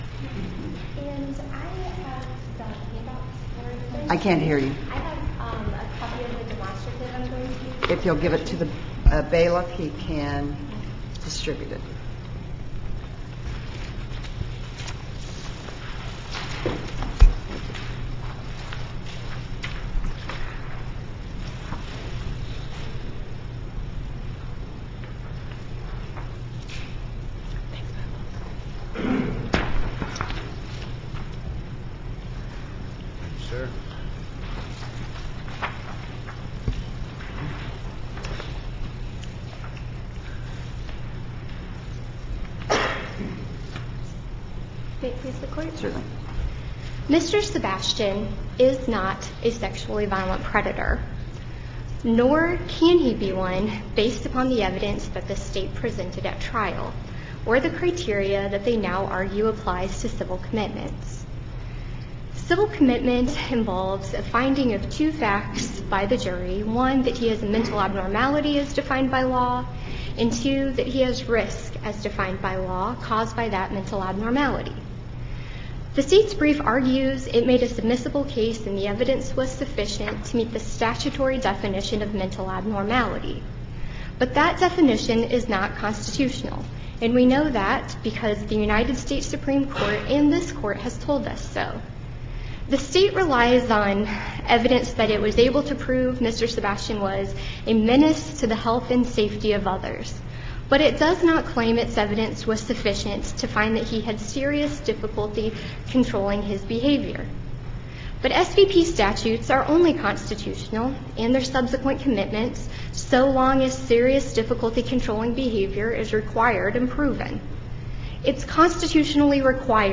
MP3 audio file of oral arguments in SC95910